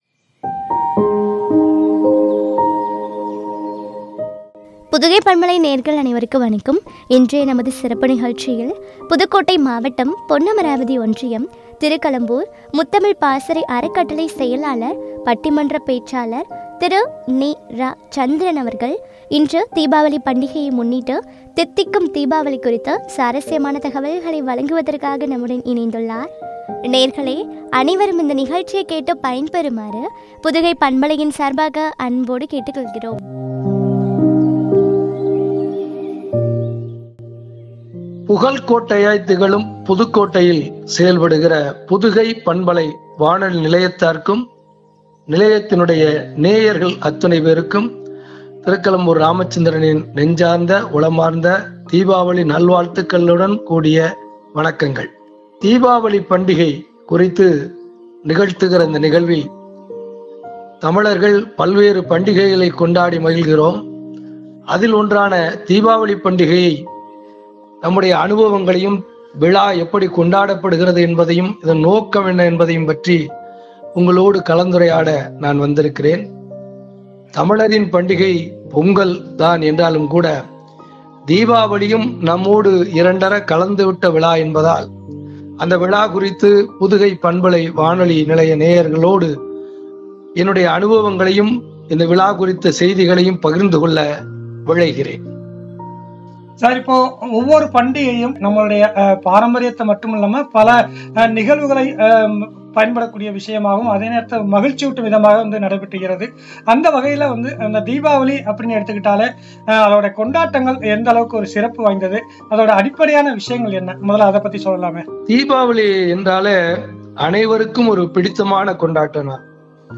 ” தித்திக்கும் தீபாவளி” குறித்து வழங்கிய உரையாடல்.